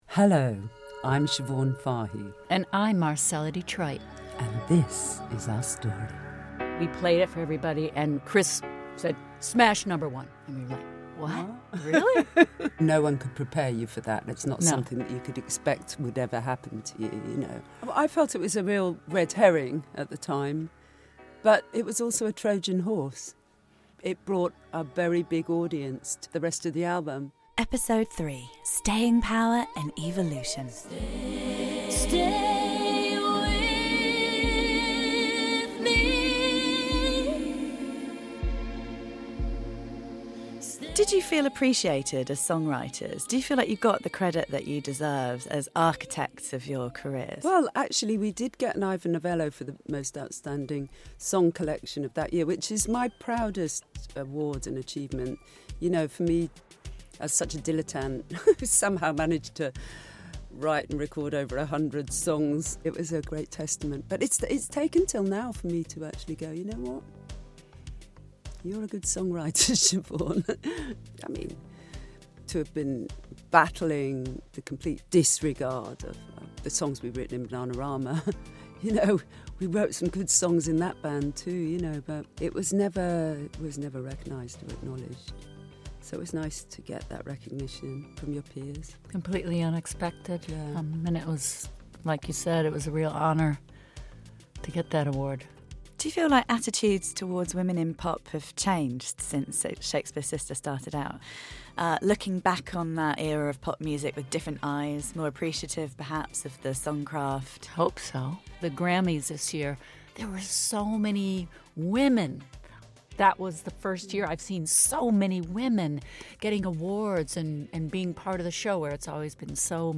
Over three episodes, released each week, Siobhan Fahey and Marcella Detroit talk candidly about exploding female stereotypes in music, their fight for authenticity and their fight with each other, as well as how Americana and Nick Cave have helped shape their new sound.